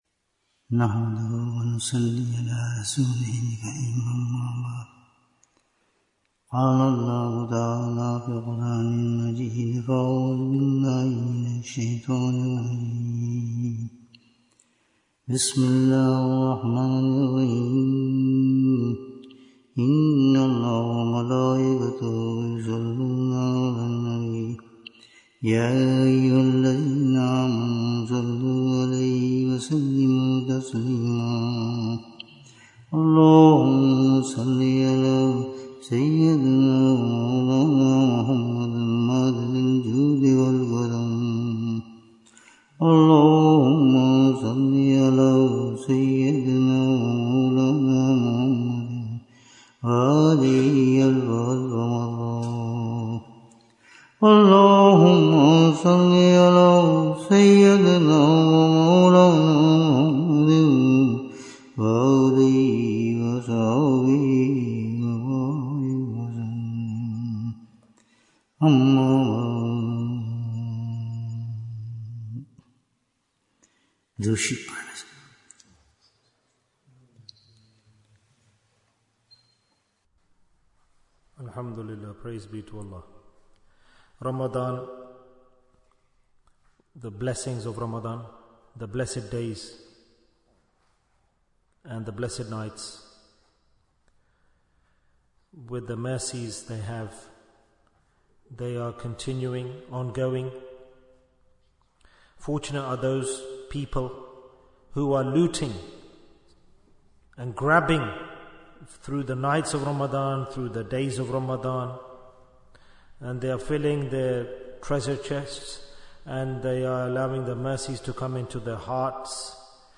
Jewels of Ramadhan 2025 - Episode 25 - Which is Better & Superior Islam? Bayan, 54 minutes19th March, 2025